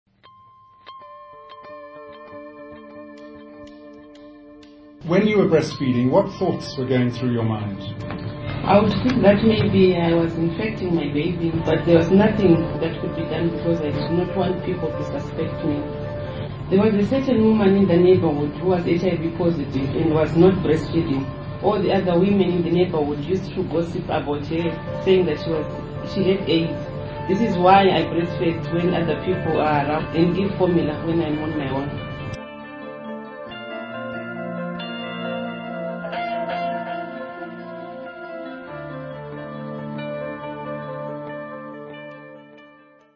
This is a     transcript from an interview with an HIV+ mother.